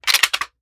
7Mag Bolt Action Rifle - Slide Forward-Down 003.wav